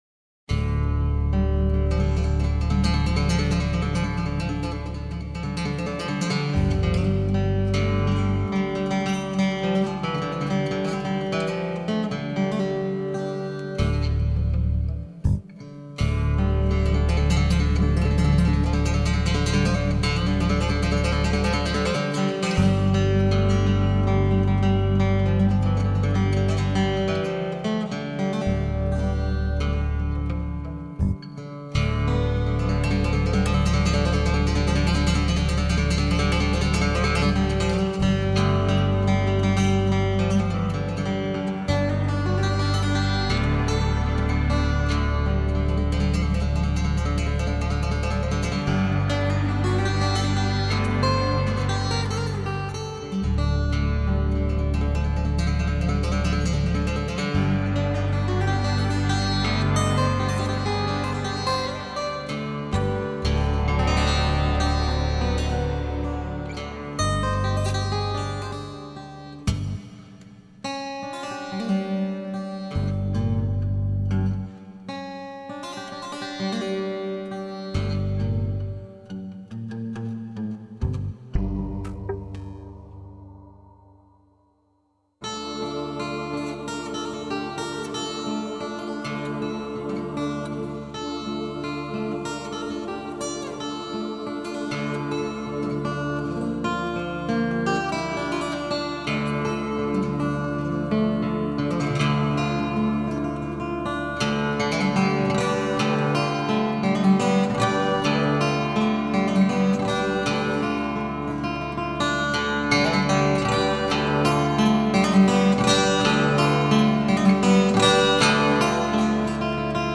Он сам сделал этот иструмент и это его музыка.
Хорошо звучит!